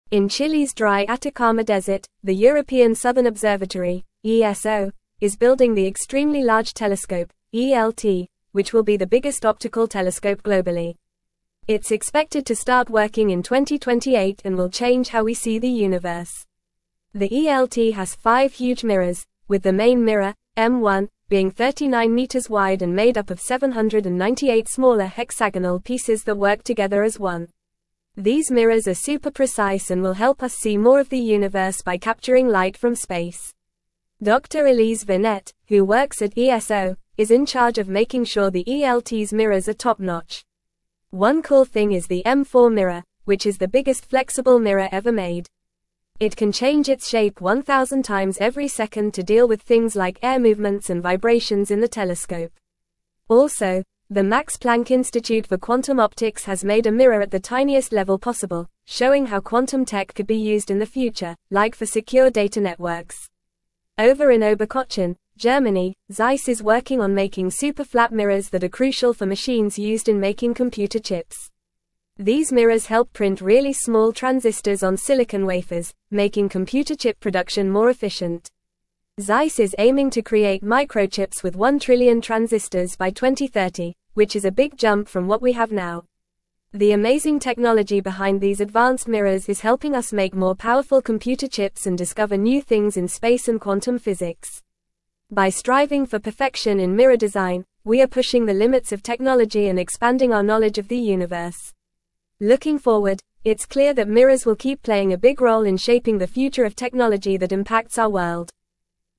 Fast
English-Newsroom-Upper-Intermediate-FAST-Reading-Advancing-Technology-Through-Precision-The-Power-of-Mirrors.mp3